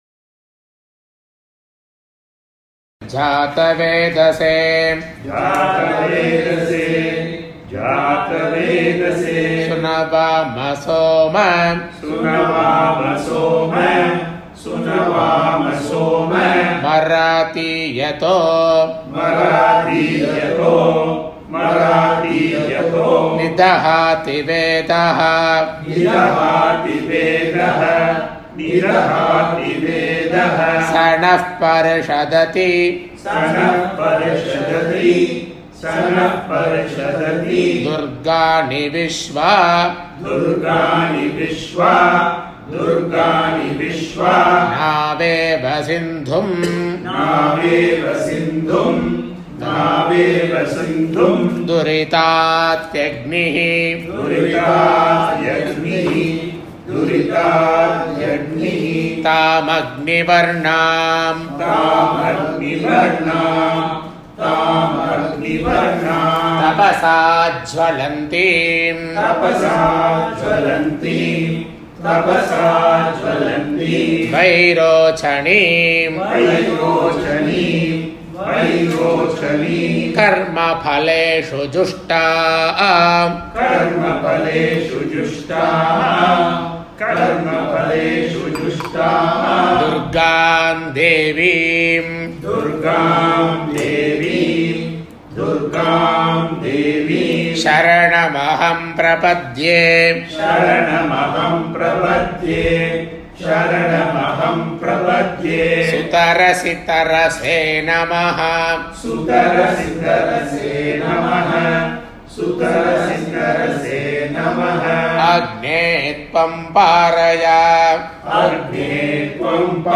Quarter line (with Students Repeat)